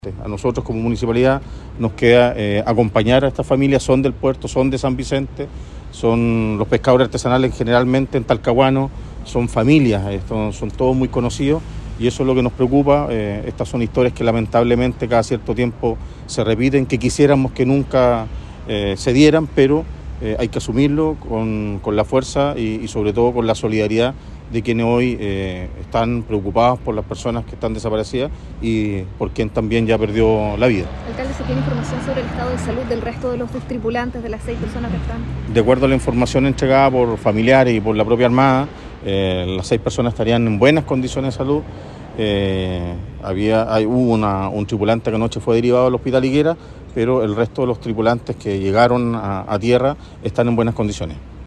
Finalmente, el alcalde de la ciudad puerto, Eduardo Saavedra, informó que la embarcación estaba conformada mayoritariamente por personas de Talcahuano.